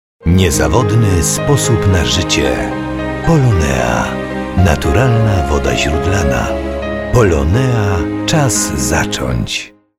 reklama radiowa #2